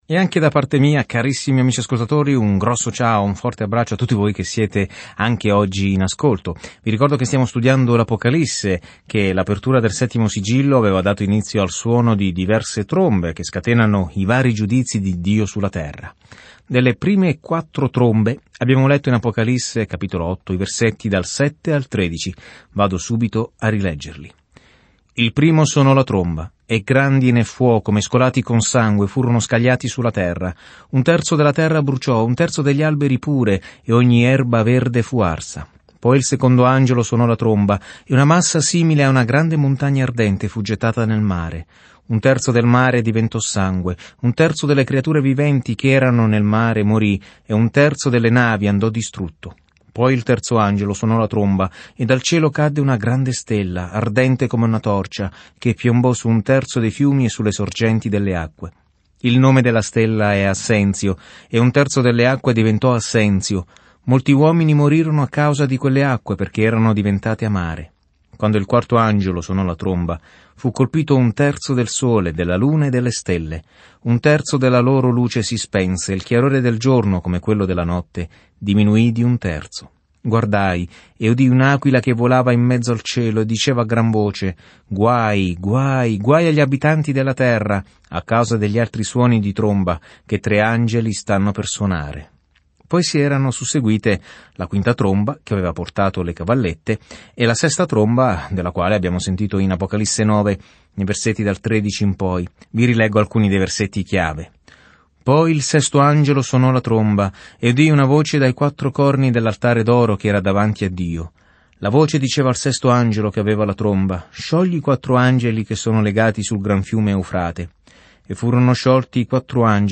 Scrittura Apocalisse di Giovanni 10:1-3 Giorno 36 Inizia questo Piano Giorno 38 Riguardo questo Piano L’Apocalisse registra la fine dell’ampia linea temporale della storia con l’immagine di come il male verrà finalmente affrontato e il Signore Gesù Cristo governerà con ogni autorità, potere, bellezza e gloria. Viaggia ogni giorno attraverso l'Apocalisse mentre ascolti lo studio audio e leggi versetti selezionati della parola di Dio.